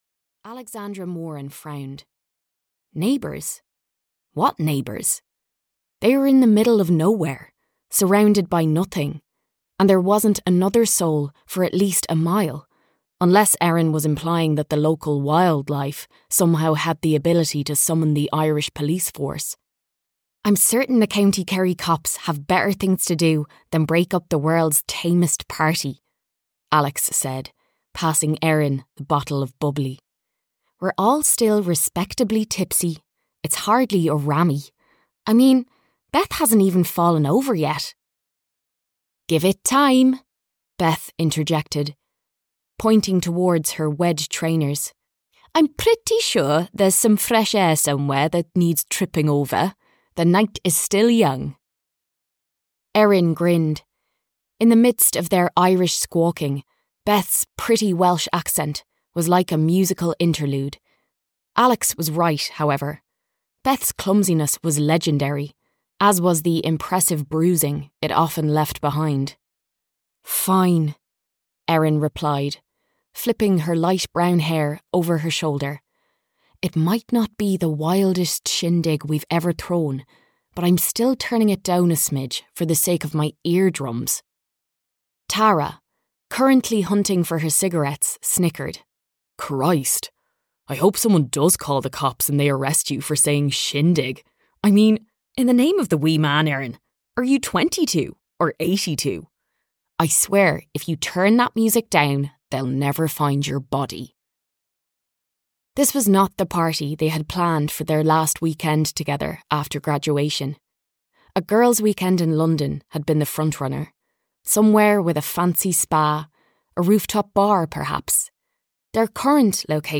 Audio knihaThe Weekend Trip (EN)
Ukázka z knihy